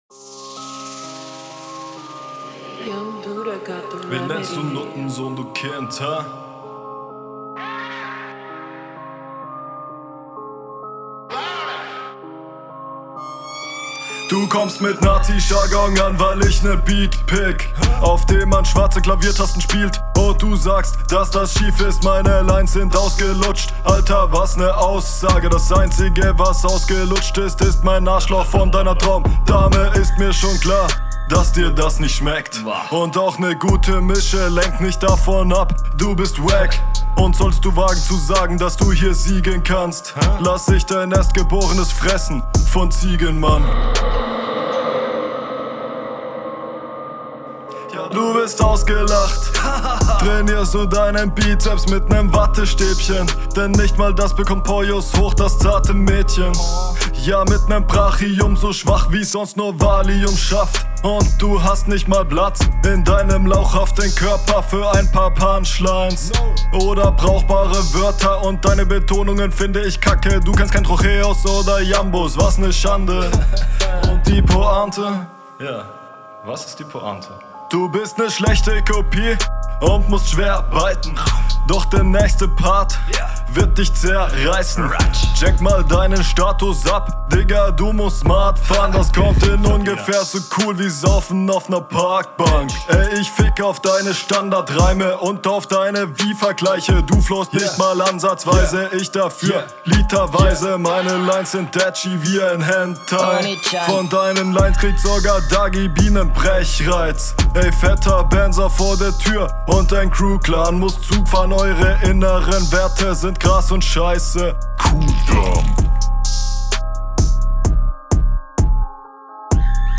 Flow: Du flowst wie in den 2 Runden wieder sehr solide.